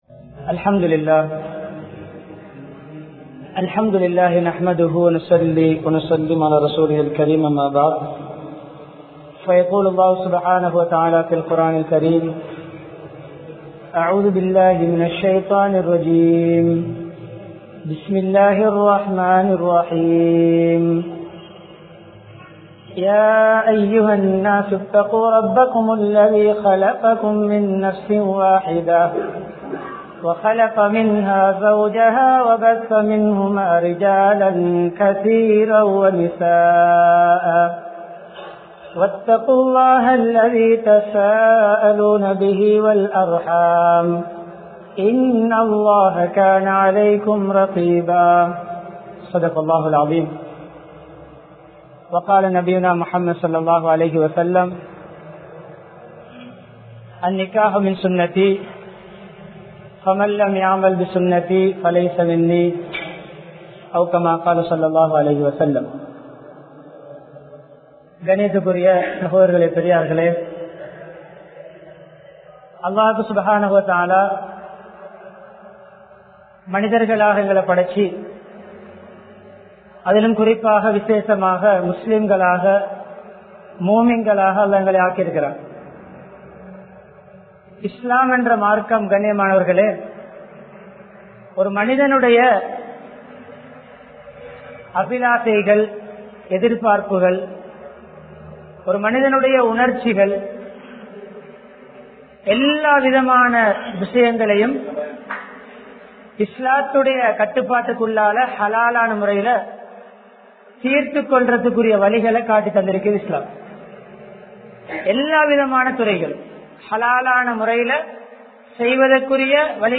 Manaiviyudan Panivaaka Nadavungal!(மனைவியுடன் பணிவாக நடவுங்கள்!) | Audio Bayans | All Ceylon Muslim Youth Community | Addalaichenai
Zaviyathul Khairiya Jumua Masjidh